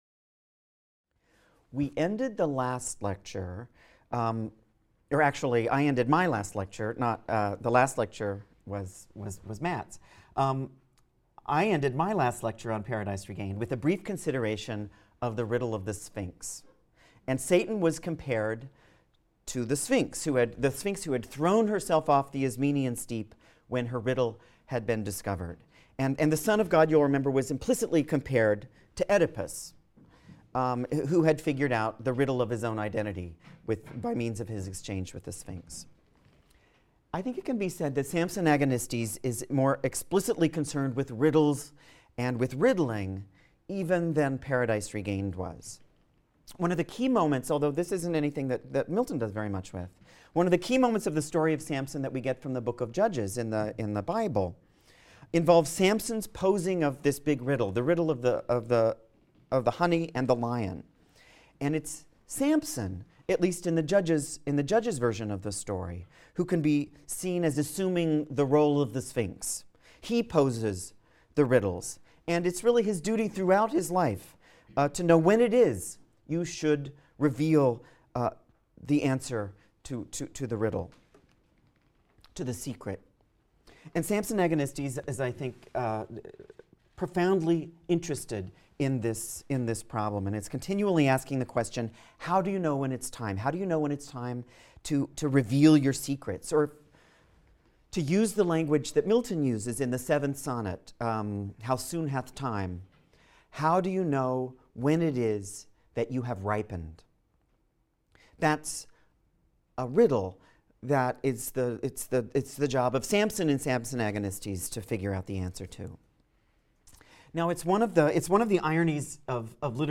ENGL 220 - Lecture 23 - Samson Agonistes | Open Yale Courses